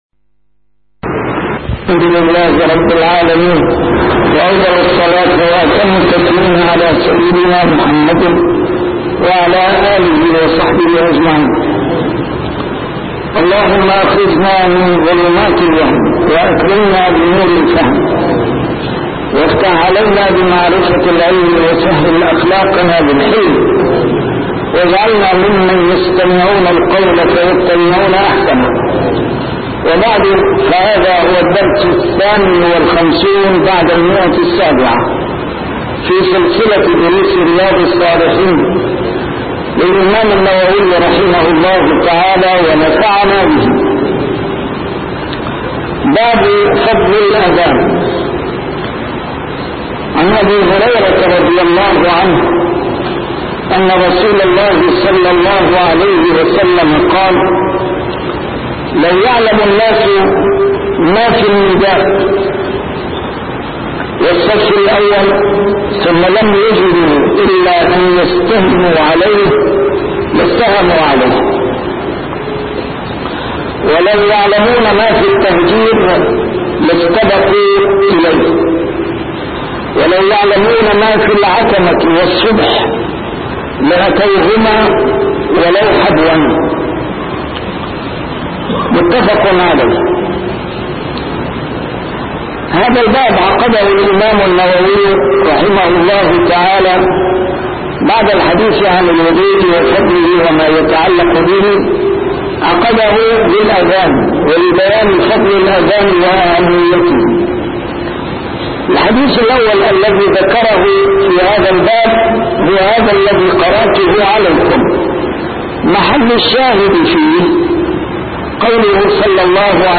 A MARTYR SCHOLAR: IMAM MUHAMMAD SAEED RAMADAN AL-BOUTI - الدروس العلمية - شرح كتاب رياض الصالحين - 758- شرح رياض الصالحين: فضل الأذان